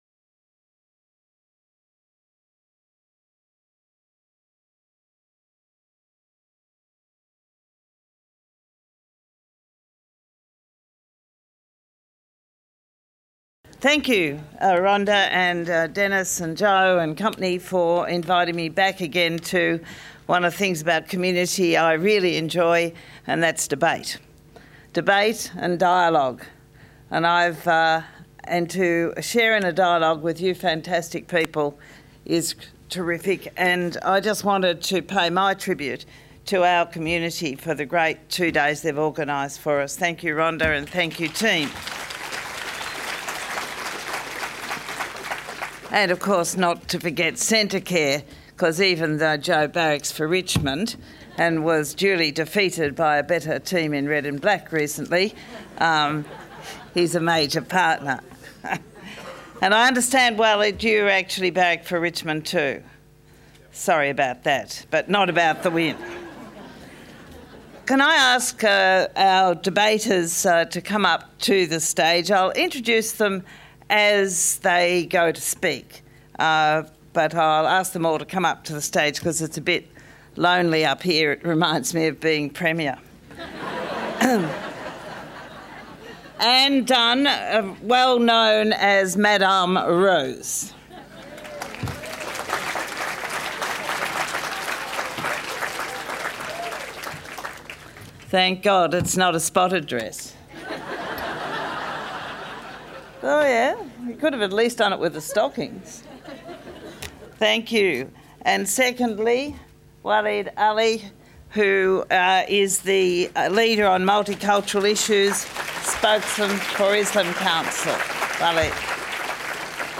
The Great Debate: Elected Governments should decide on community…